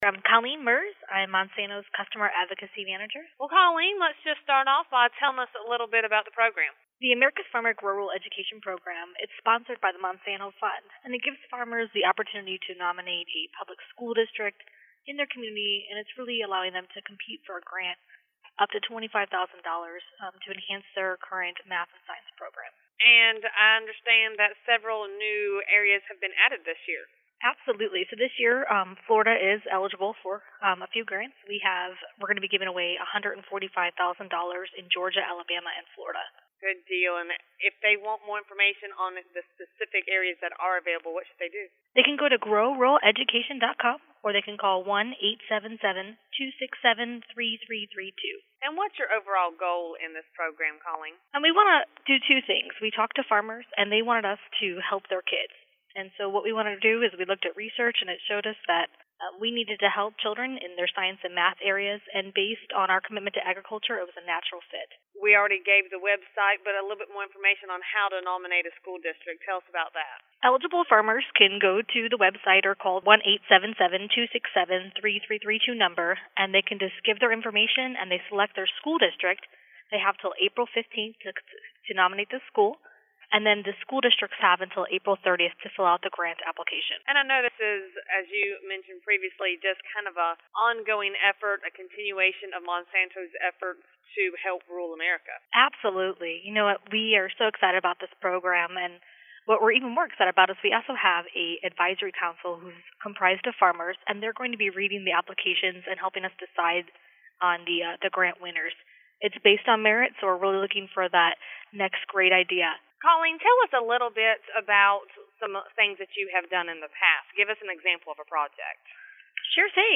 For the second year in a row, the Monsanto Fund is gearing-up to invest $2.3 million to strengthen math and science education in rural communities through America’s Farmers Grow Rural Education. In the following interview